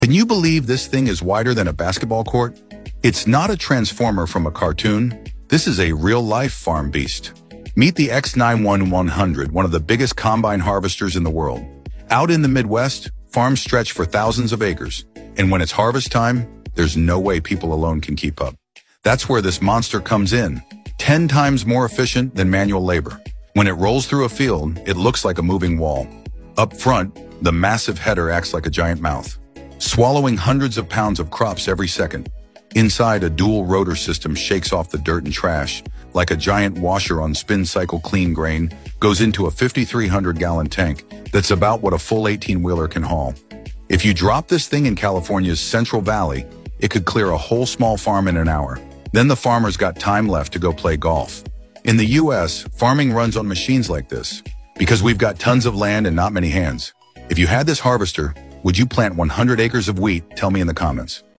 The world's largest combine harvester